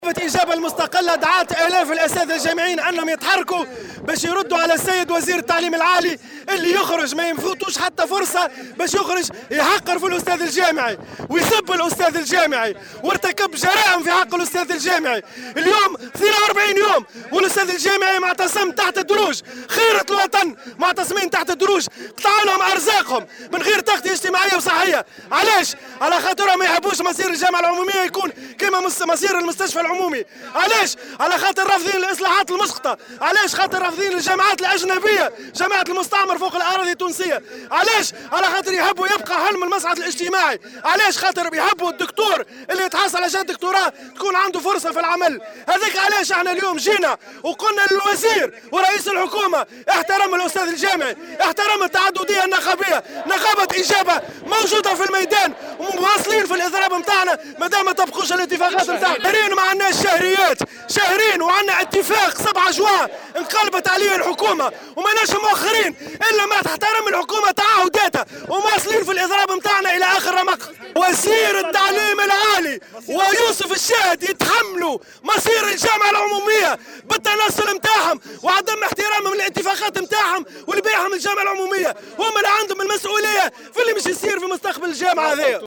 خلال مسيرة في شارع الحبيب بورقيبة : "إجابة" يتمسّك بمقاطعة الامتحانات